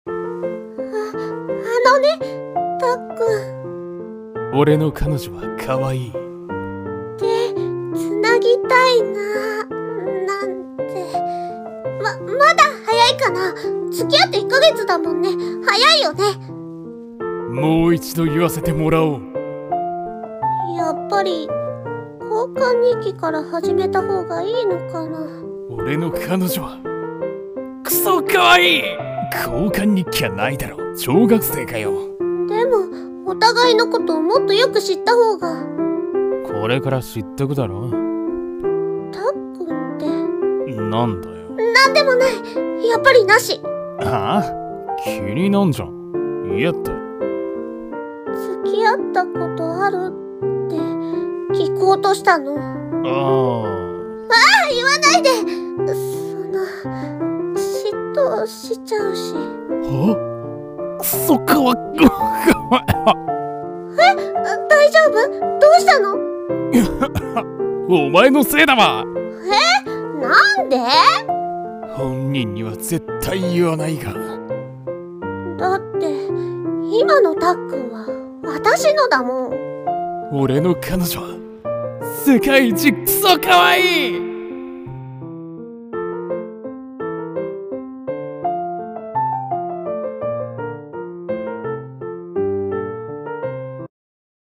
【2人声劇】(かわいい)